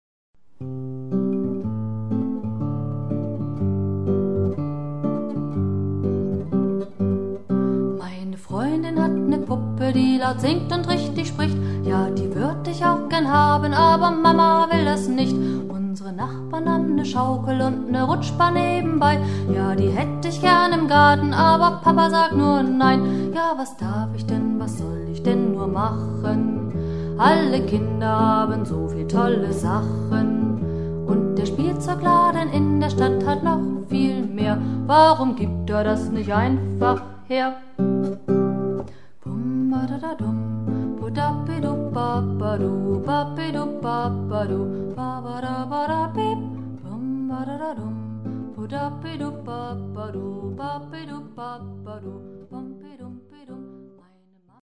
Auszug einer Privataufnahme als mp3-Datei